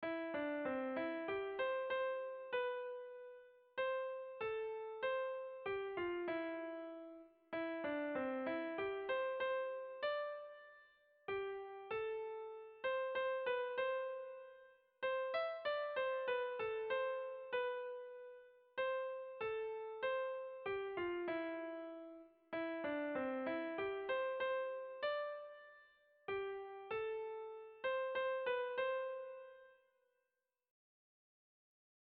Sentimenduzkoa
Hamarreko txikia (hg) / Bost puntuko txikia (ip)
A1A2BA2